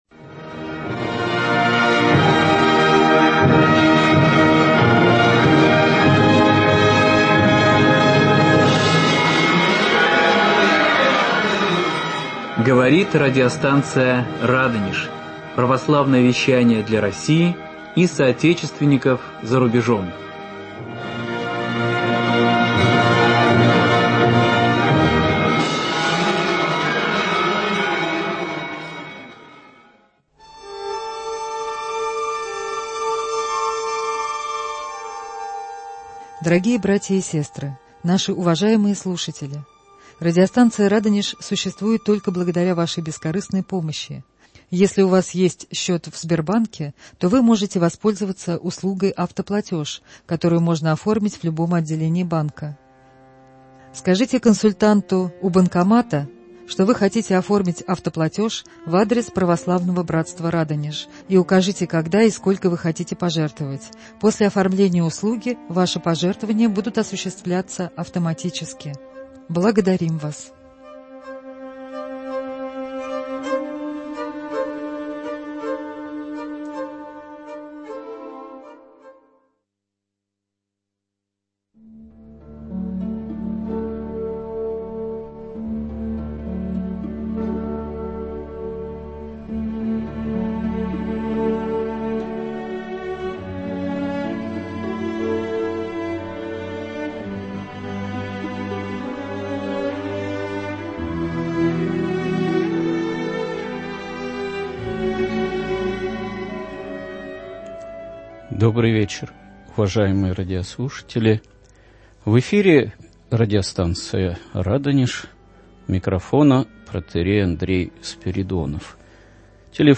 Ответы на вопросы радиослушателей.